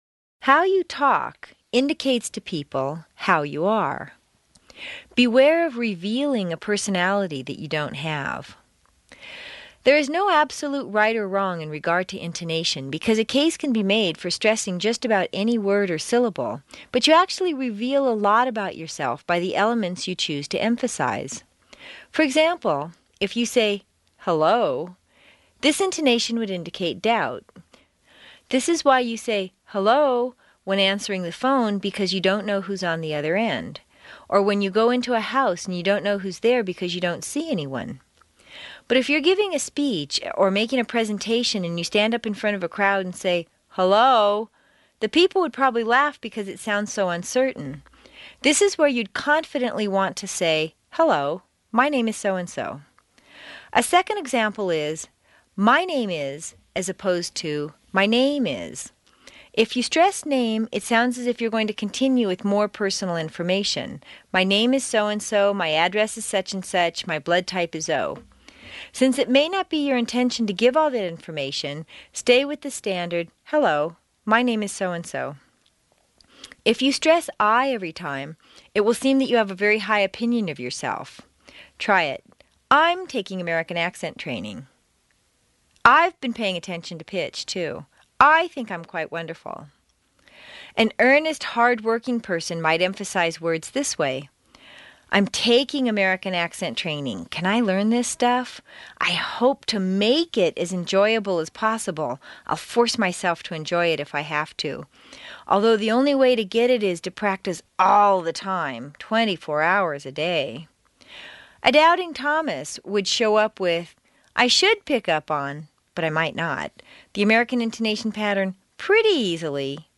美语口音训练第一册24 听力文件下载—在线英语听力室